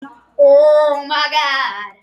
Oh My God Téléchargement d'Effet Sonore
Oh My God Bouton sonore